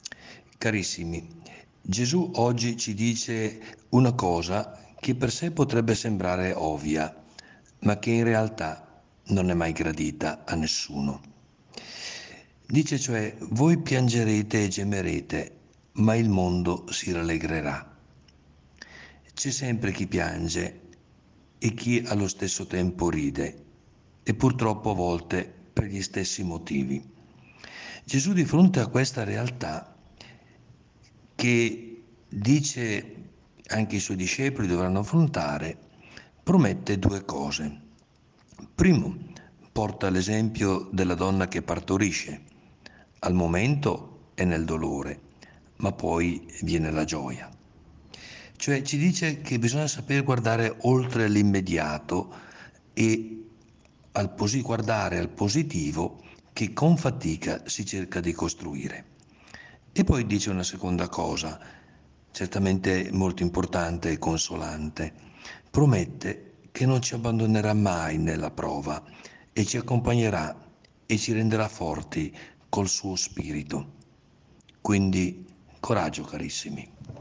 Il Vescovo commenta la Parola di Dio per trarne ispirazione per la giornata.